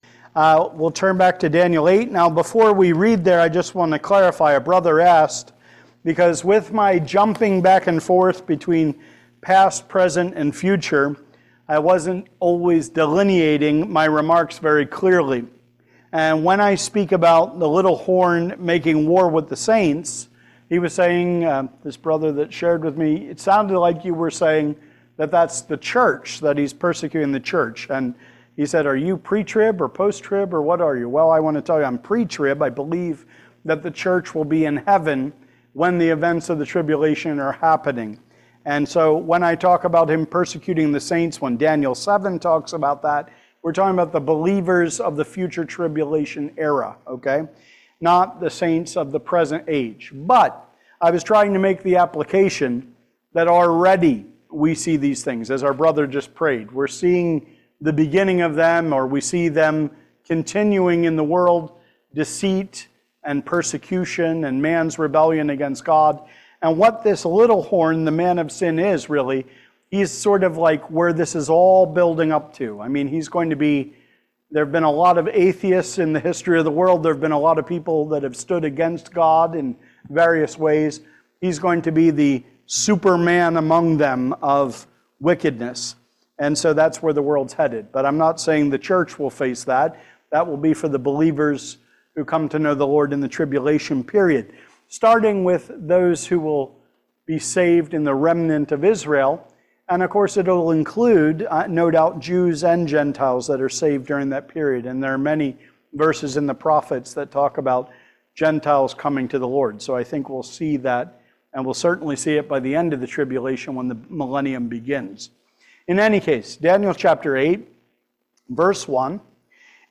Seminar
Passage: Daniel 8 Service Type: Special Meeting